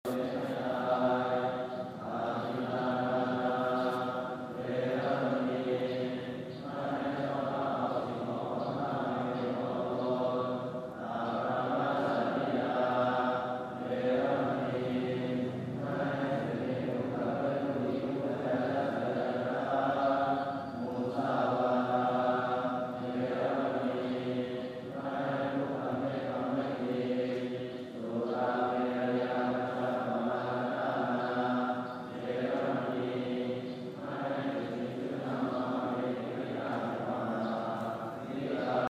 어느 날에는 바깥을 산책하는 것으로 그치지 않고 어떤 소리를 따라 안으로 들어갔다. 지긋하신 어른들도 일터의 옷을 입은 어느 젊은이도 제 자리를 잡고 앉아 차분히 손을 모았다. 귀에 흘러들어오는 소리는 이해될 길이 없고, 어떤 모양으로 예를 갖추어야 하는지도 알 길이 없지만 원래 내 생활이었던 양 나도 차분히 손을 모으고 그렇게 한참을 앉아있었다.
가만히 앉아 이 소리를 듣고 있자니 그저 내 생활인 듯하다.